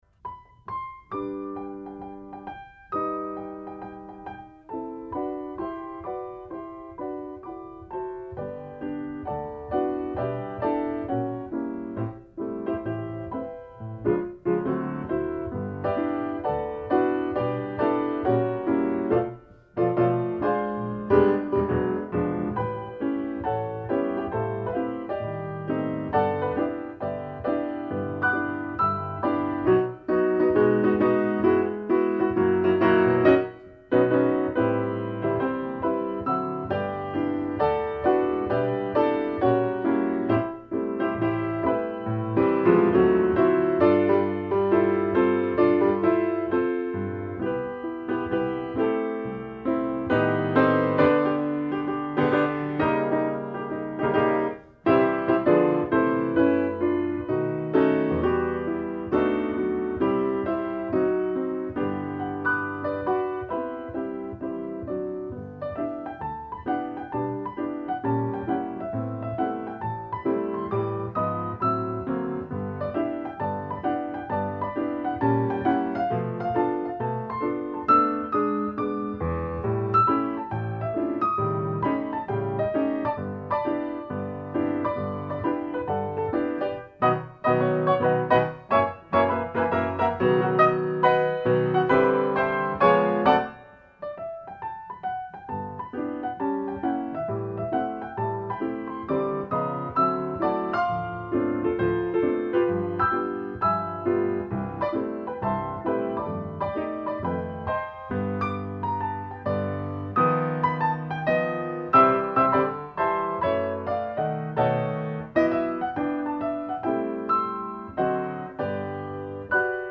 1941   Genre: Soundtrack   Artists